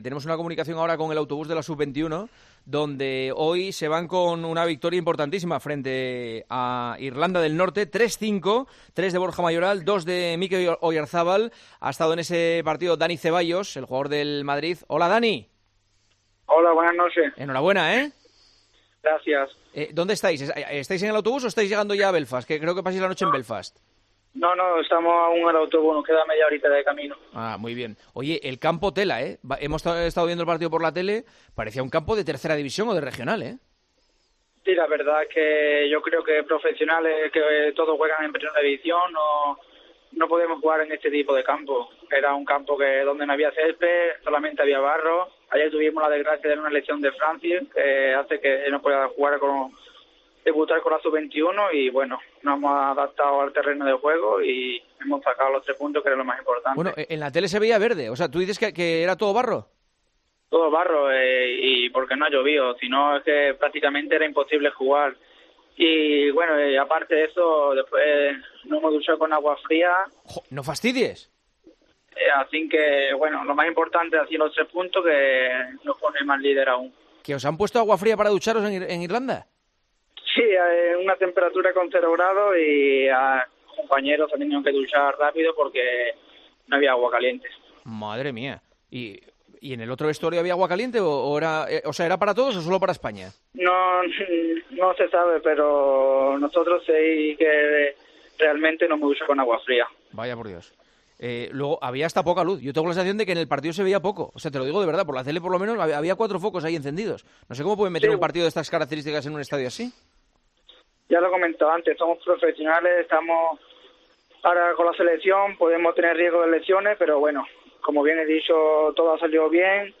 Dani Ceballos atendió la llamada de El Partidazo de COPE este jueves, después de ganar a Irlanda del Norte con la selección sub-21 (3-5) en un estadio "con barro y riesgo de lesiones, aunque nos terminamos adaptando al terreno de juego y hemos ganado los tres puntos, que es lo importante".